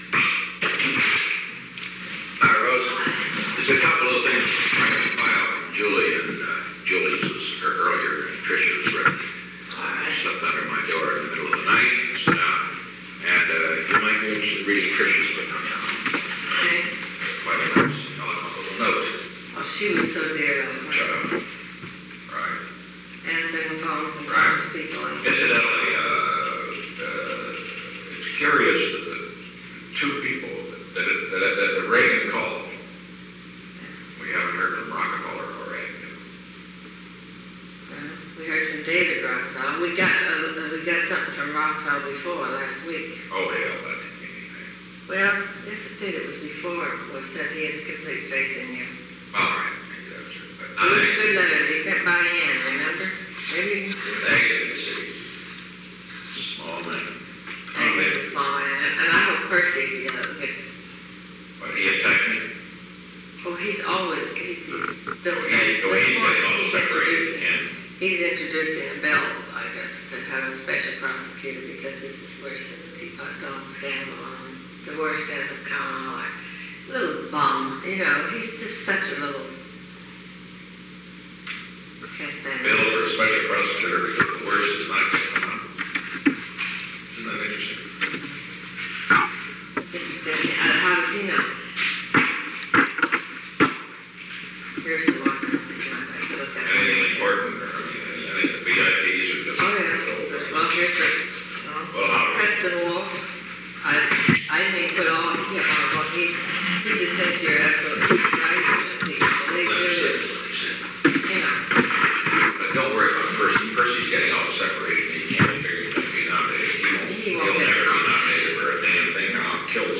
Secret White House Tapes
Conversation No. 908-16
Location: Oval Office
The President met with Rose Mary Woods.